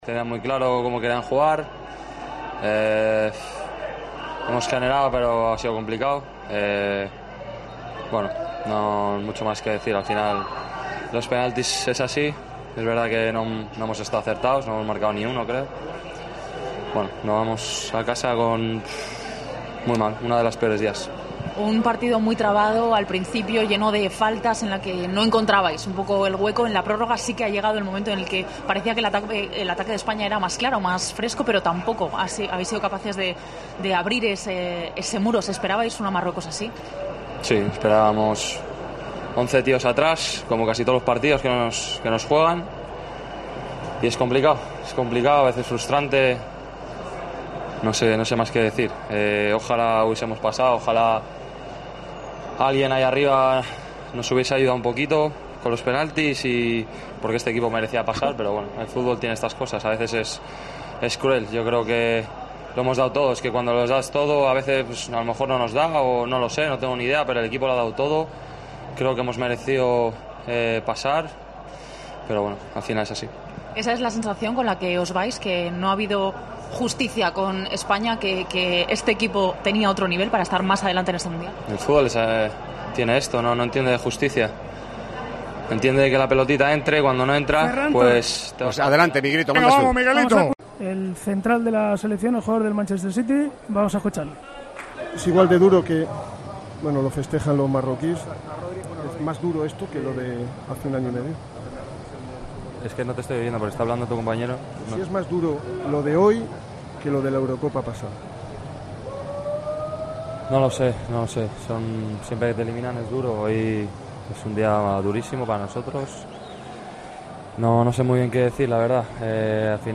Día durísimo para la selección española que representó perfectamente Rodri Hernández, central de la selección española, que se vio abatido en el post-partido.